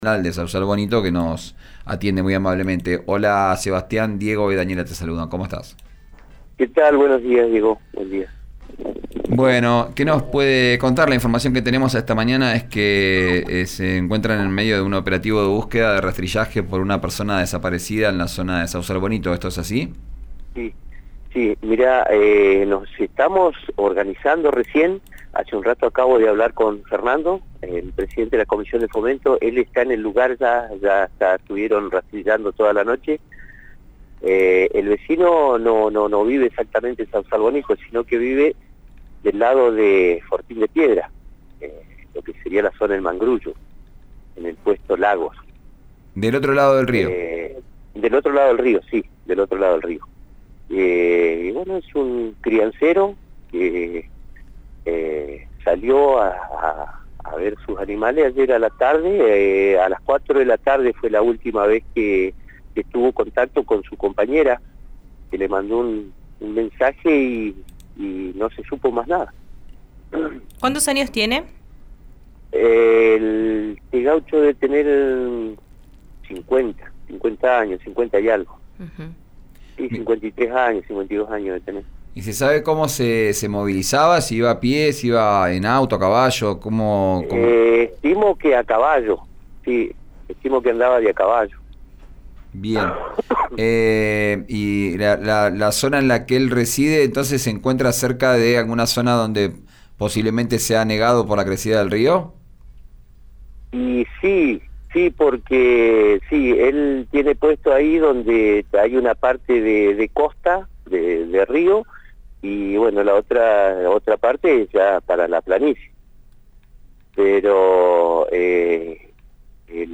expresidente de la comisión de fomento dijo en diálogo con «Vos Al Aire» en RÍO NEGRO RADIO que entre los vecinos se habían organizado para buscarlo.